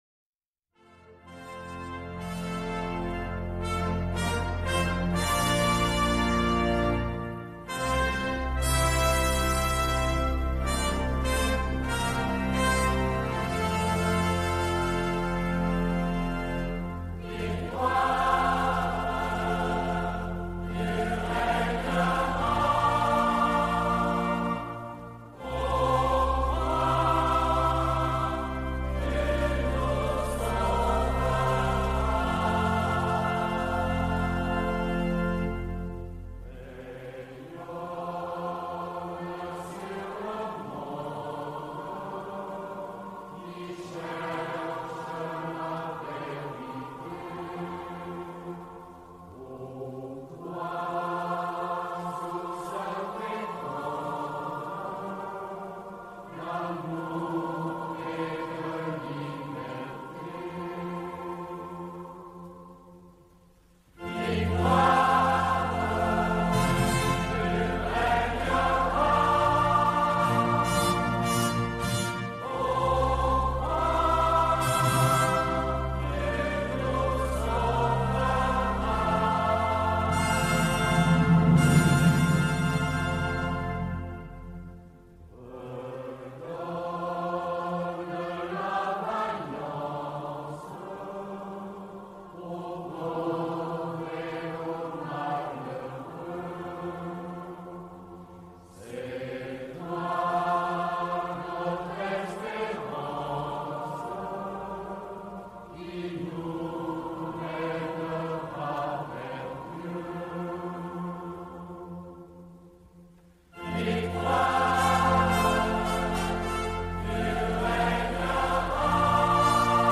Eglise Saint-François-de-Paule Fréjus - 1er dimanche de Carême